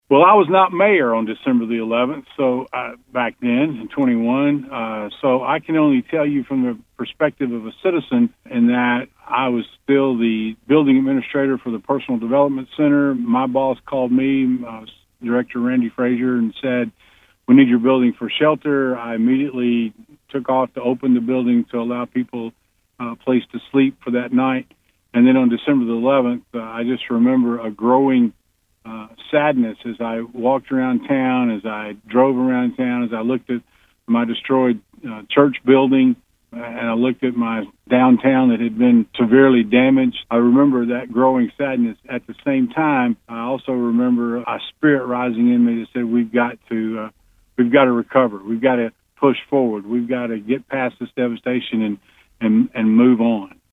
Current Dresden Mayor Mark Maddox reflects on the day following the devastation…